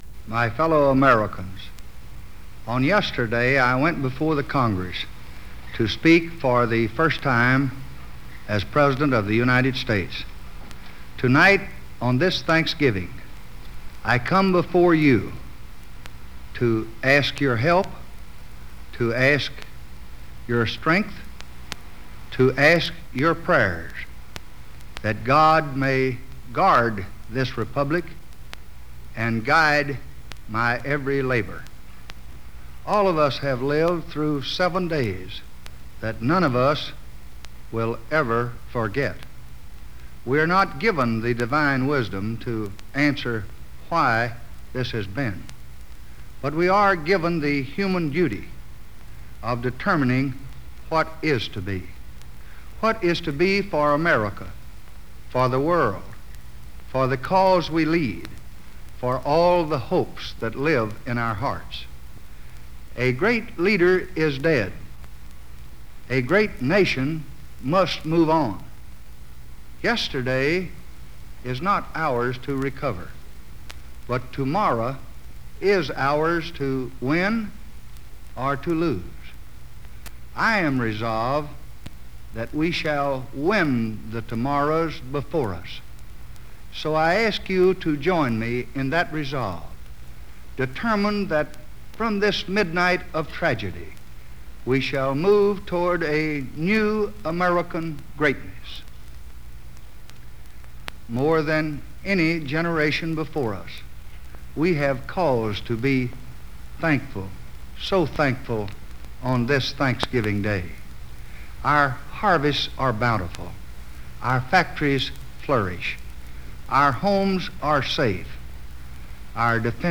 U.S. President Lyndon B. Johnson delivers a public address on Thanksgiving Day 1963